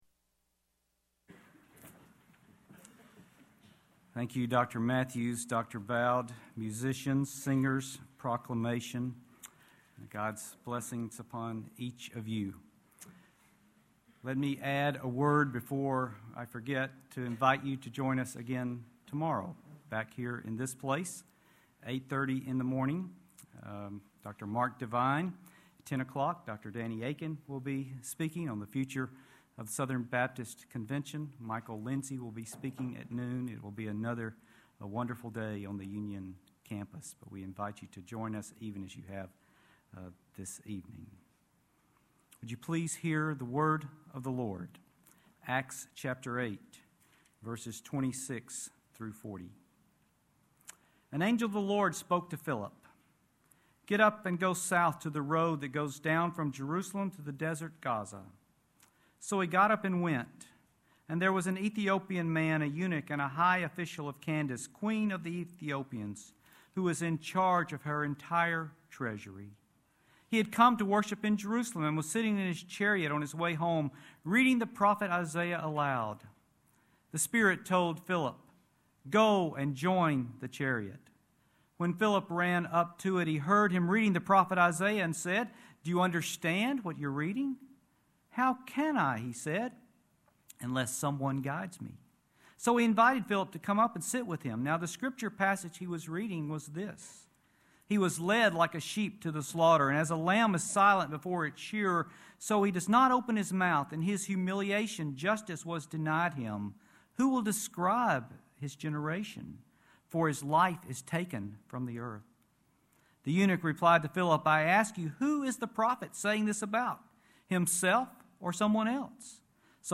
Address: Corporate Worship